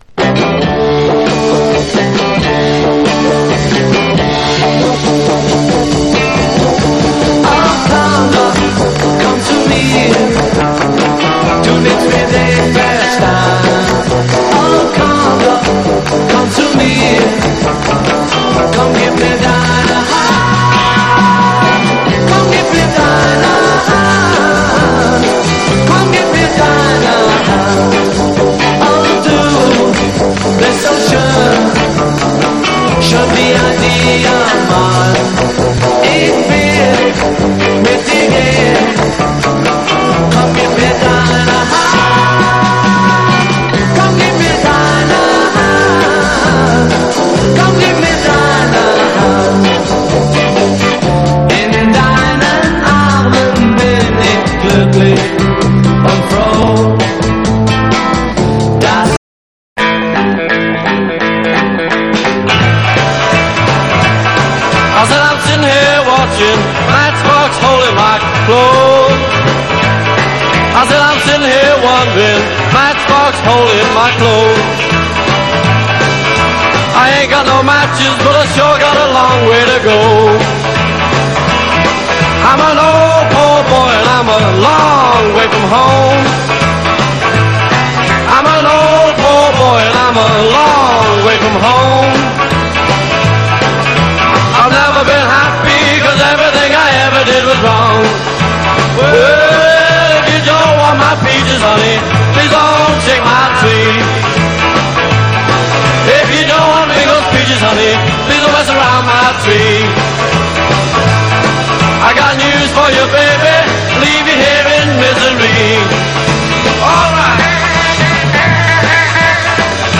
ROCK / 60'S